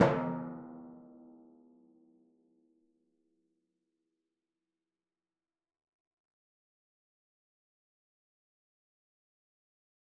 Timpani4_Hit_v4_rr2_Sum.wav